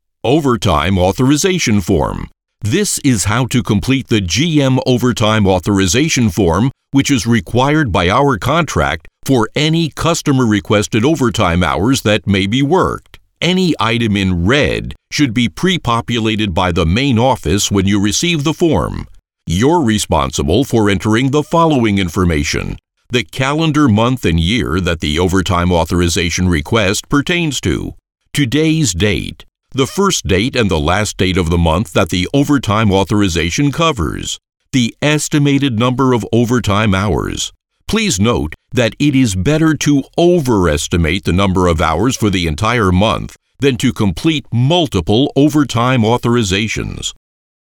Comercial, Profundo, Natural, Llamativo, Amable
E-learning